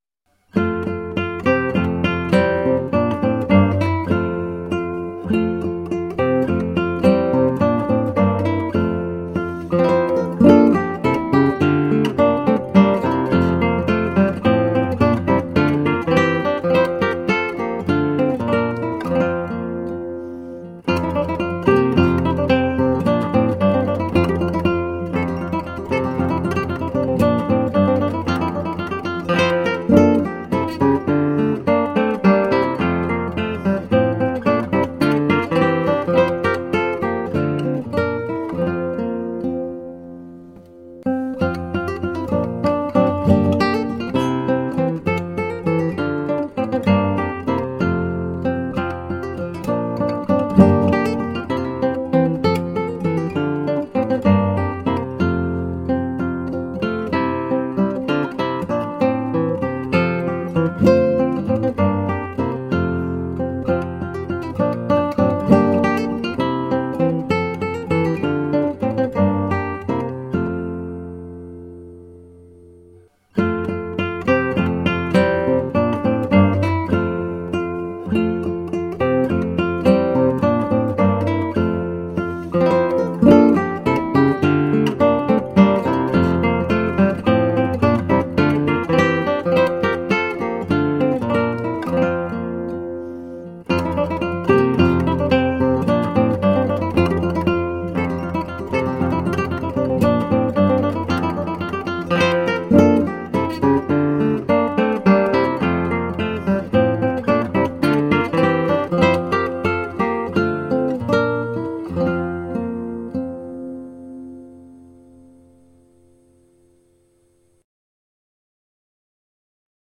carols
simple acoustic guitar